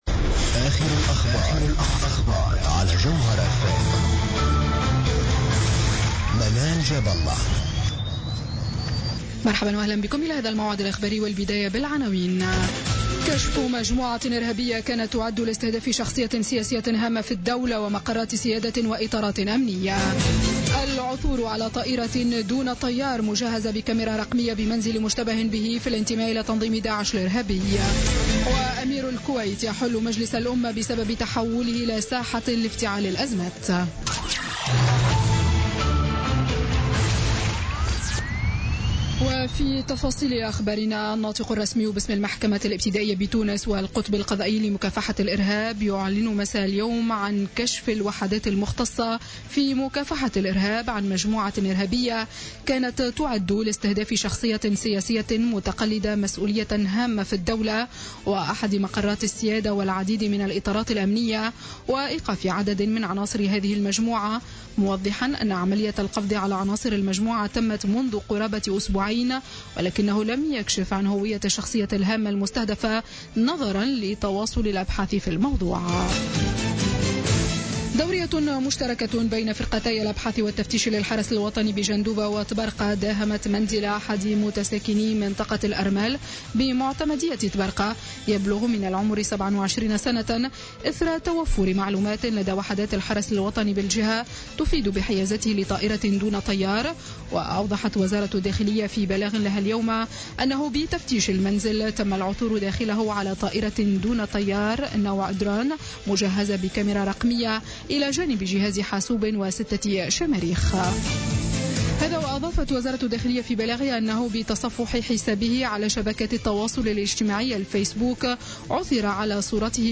نشرة أخبار السابعة مساء ليوم الأحد 16 أكتوبر 2016